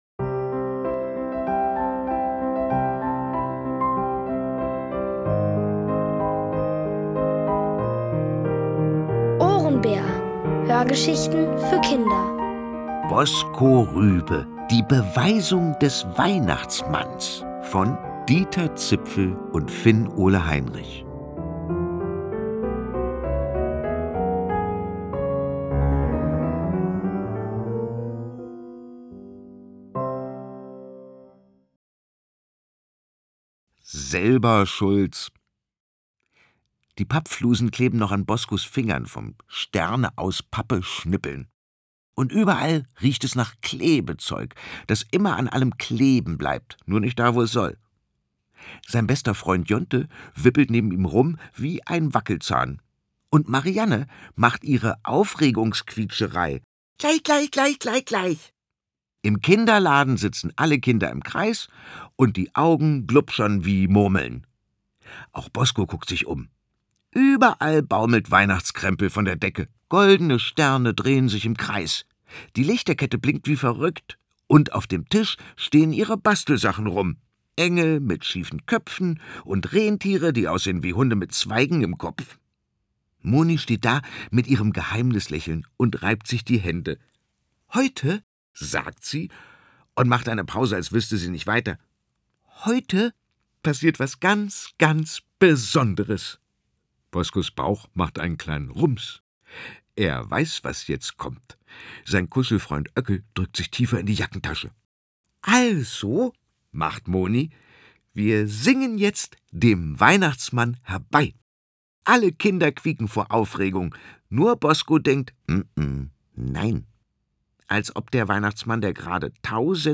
Erzählt von Boris Aljinovic.